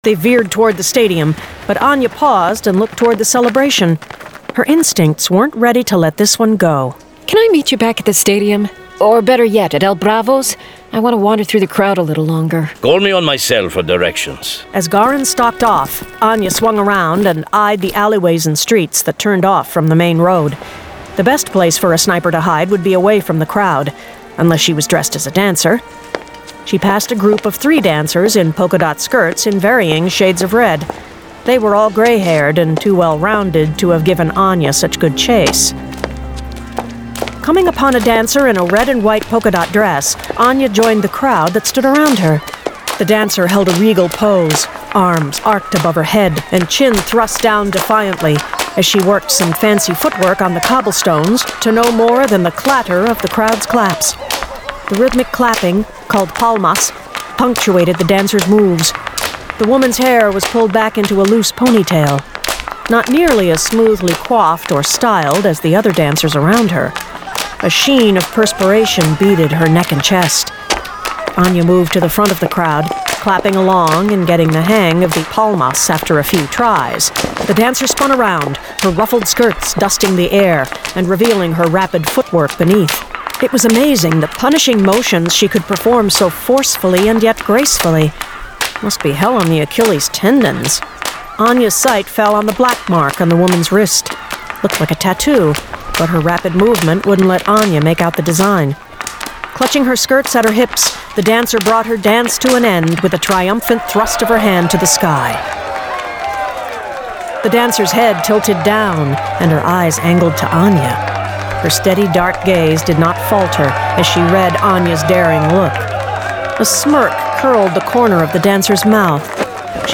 Rogue Angel 38: The Matador's Crown [Dramatized Adaptation]
Full Cast. Cinematic Music. Sound Effects.